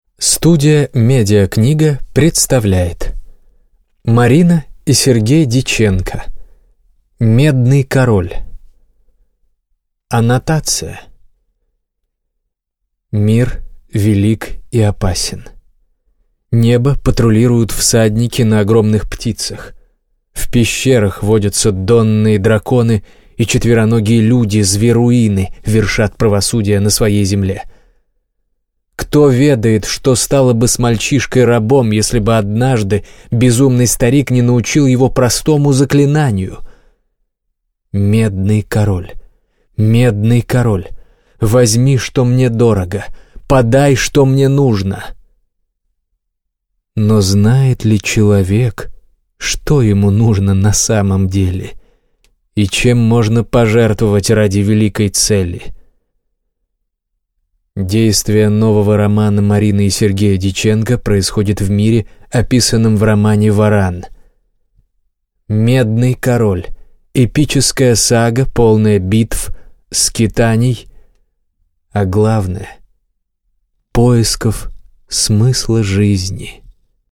Аудиокнига Медный король | Библиотека аудиокниг